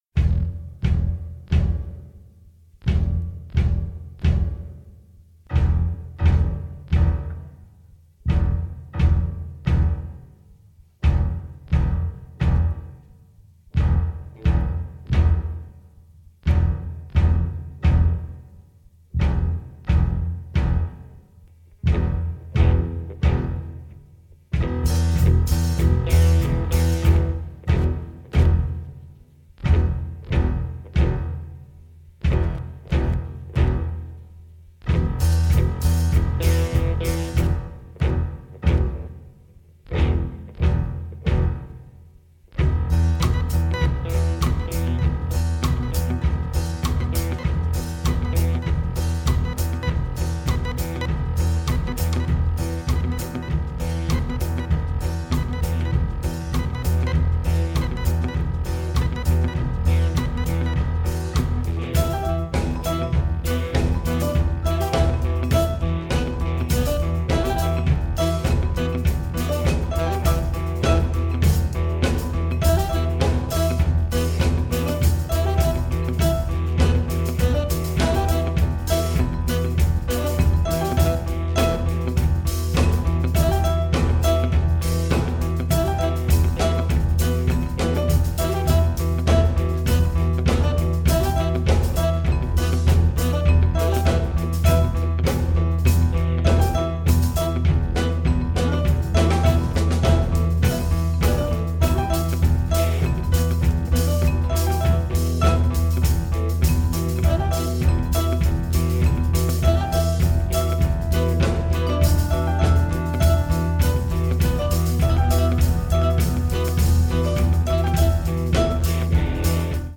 ジャズやアヴァンギャルド、ロック等を孕みつつ、ミニマリズム的な反復を多用したチェンバー・ミュージック！